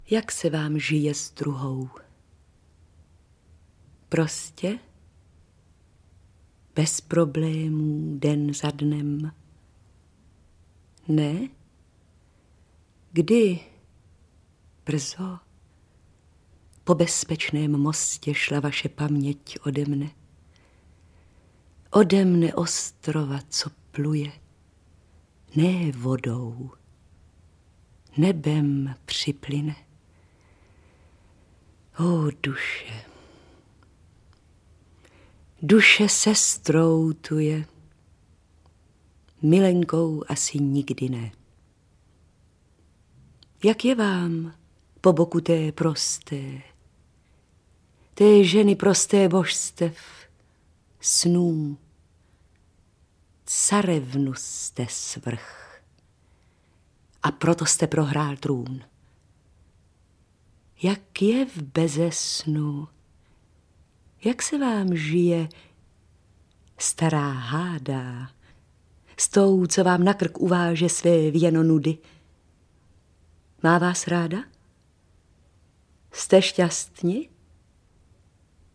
Básně Jak se vám žije s druhou?, Plavené dříví, Hodina duše, Až přijde den a Když se tak dívám recitují Dana Medřická a Jana Dítětová.
beletrie / poezie